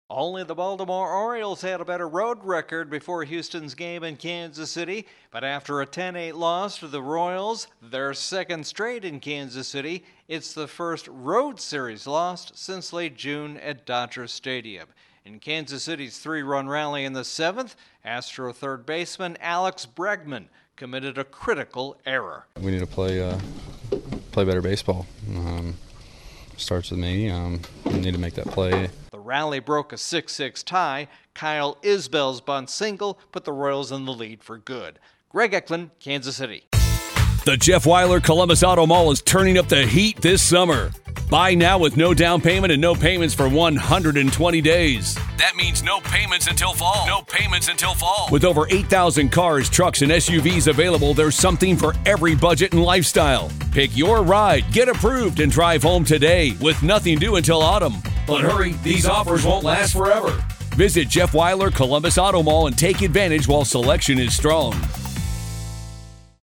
The Astros finally absorb a series loss on the road. Correspondent